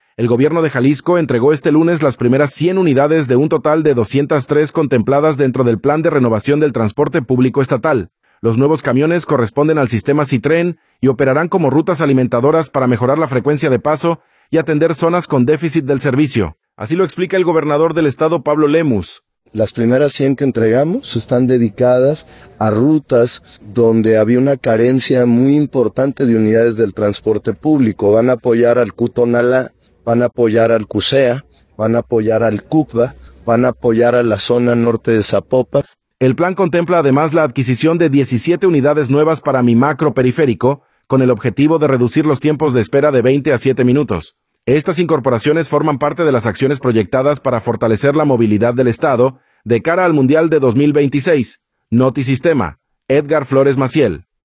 Los nuevos camiones corresponden al sistema Sitren y operarán como rutas alimentadoras para mejorar la frecuencia de paso y atender zonas con déficit del servicio. Así lo explica el gobernador del estado Pablo Lemus.